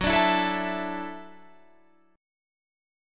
Listen to F#m7#5 strummed